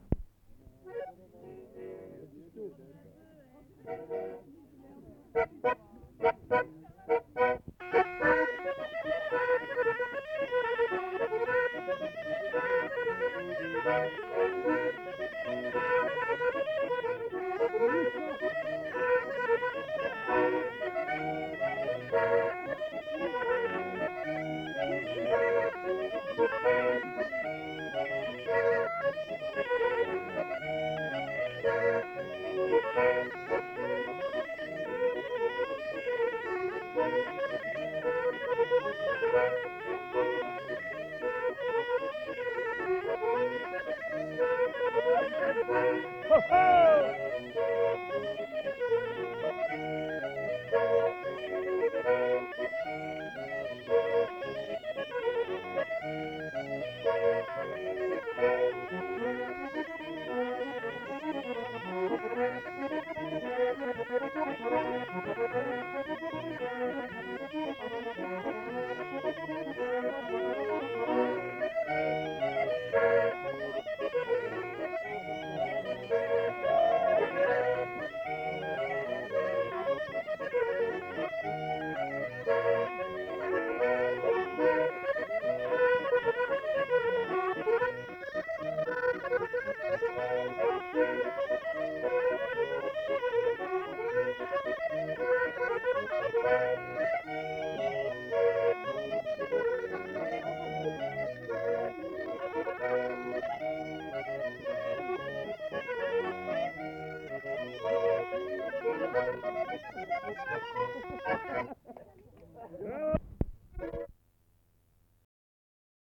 Bourrée
Aire culturelle : Viadène
Département : Aveyron
Genre : morceau instrumental
Instrument de musique : cabrette ; accordéon chromatique
Danse : bourrée